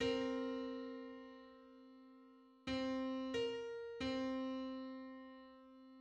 Just: 57:32 = 999.47 cents.
Public domain Public domain false false This media depicts a musical interval outside of a specific musical context.
Fifty-seventh_harmonic_on_C.mid.mp3